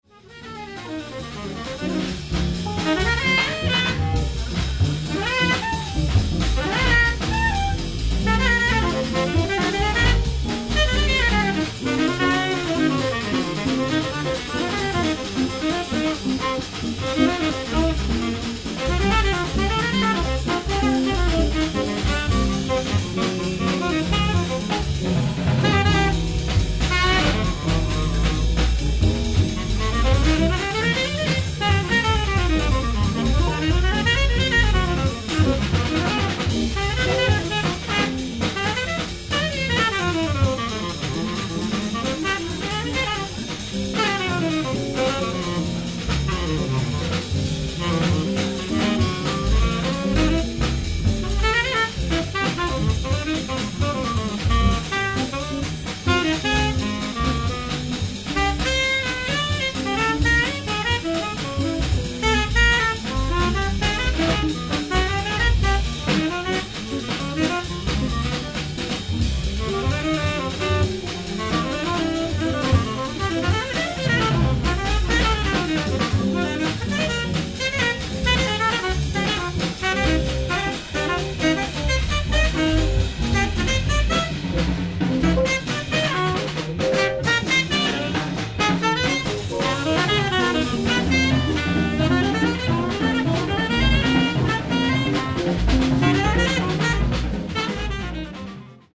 ライブ・アット・ヴィレッジ・ヴァンガード、ニューヨーク 09/02/1992
※試聴用に実際より音質を落としています。